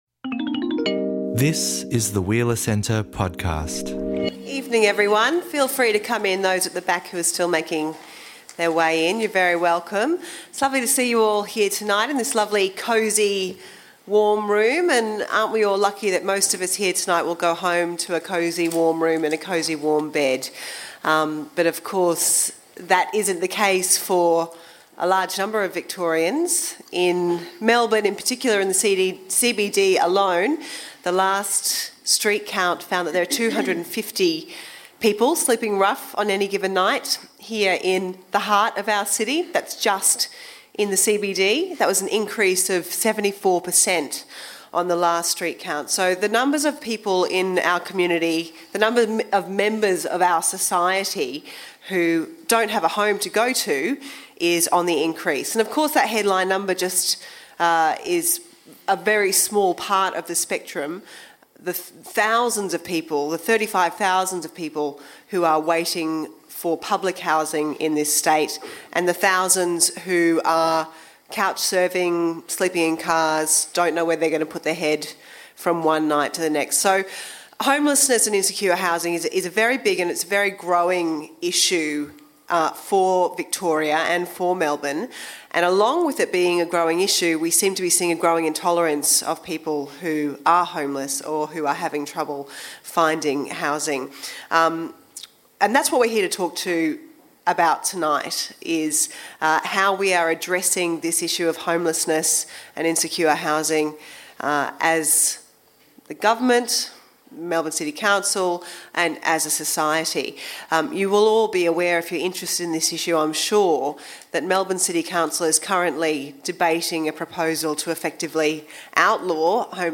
In a full hour of audience Q&A, we’ll delve into some complex problems that demand urgent solutions.